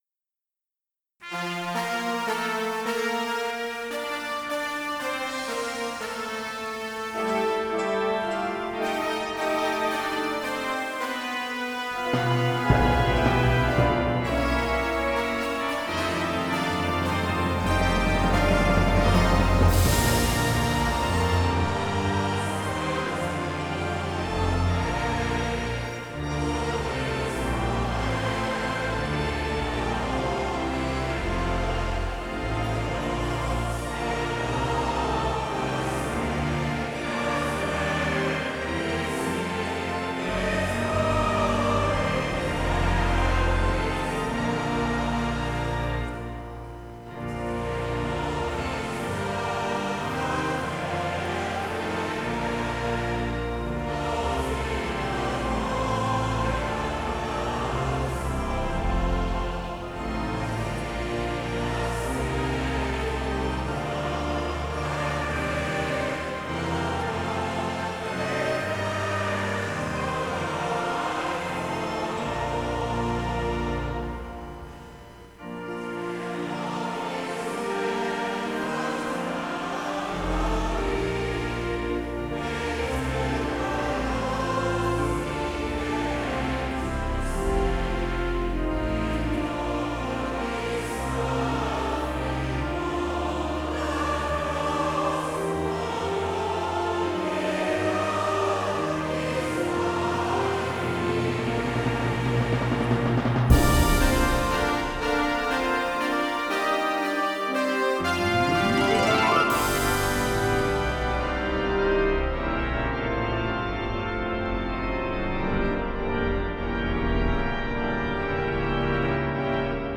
This track is a bodge up of 3 different events, beautifully smoothed over to give you a recording of one of the 6.30pm evening service classics from CCC in the late 1980s. The singing of the selected verses is from Aberystwyth, Wales in 1986 with a tinsey-winsey  bit from Clifton and an interlude (mostly touched up) from Bristol Cathedral at dates not recorded.
Hymn Tune: ‘Fulda’, William Gardiner